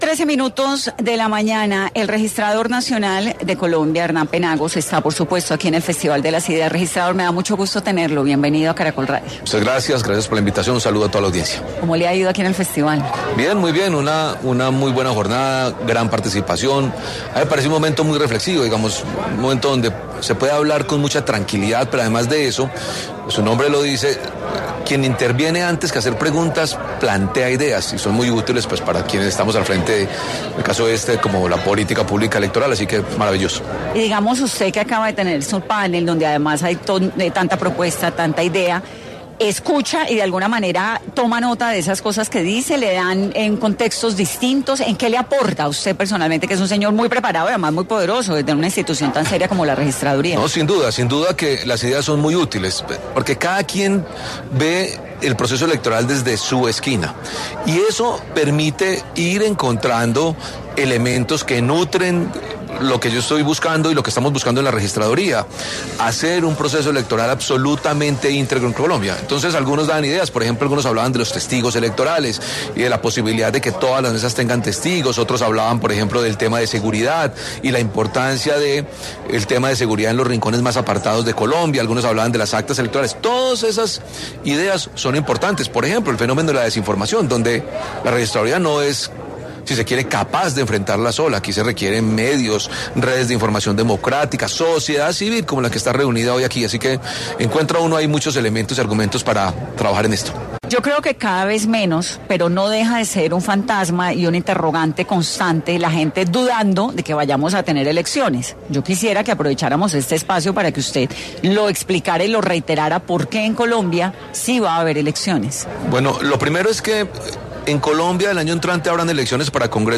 El registrador, Hernán Penagos, en el Festival de las Ideas afirmó que desde la entidad avanzan con acciones que permitan mayor transparencia.